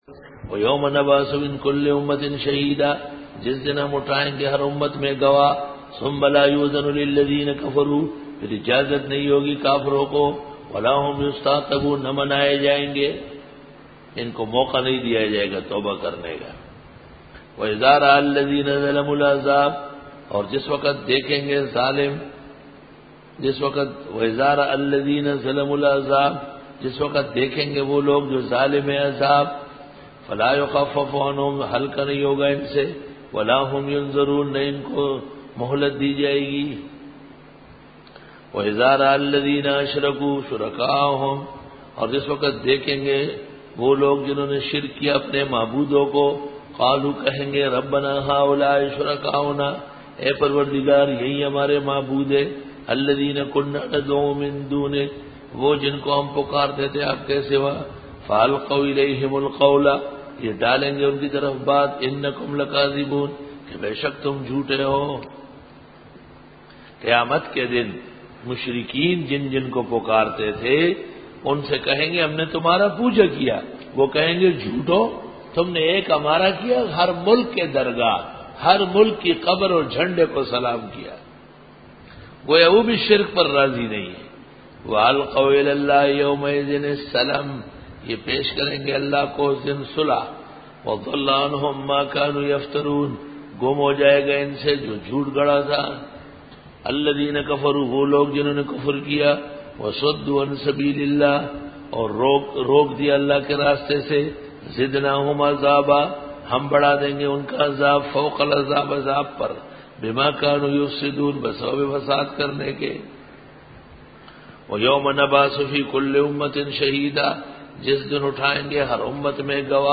Dora-e-Tafseer 2007